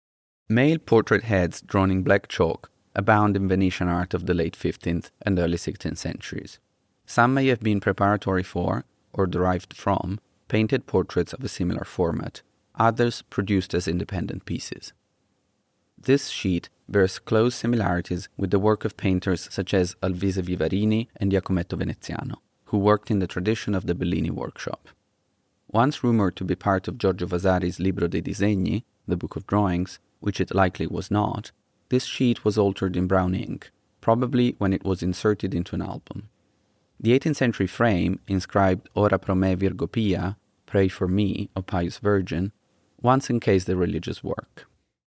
Each object is accompanied by an audio recording of the label text.